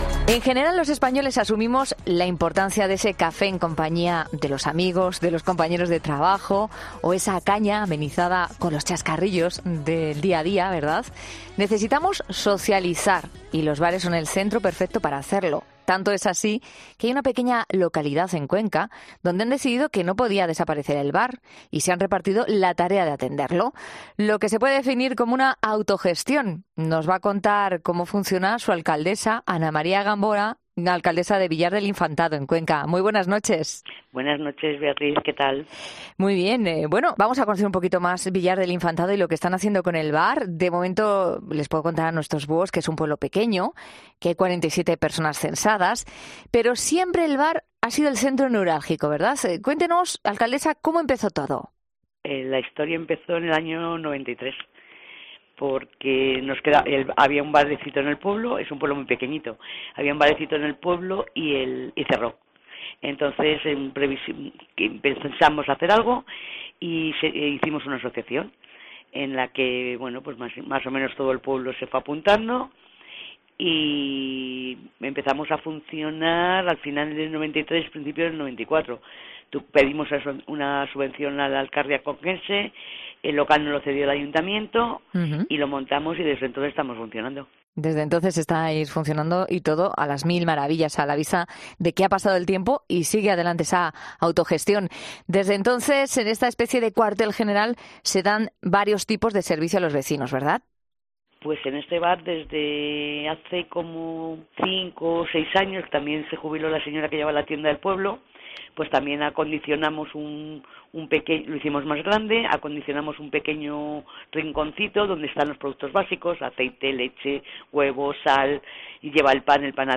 Hablamos con Ana María Gamboa, la alcaldesa de este pequeño municipio que nos cuenta la curiosa historia que esconde el bar de su pueblo.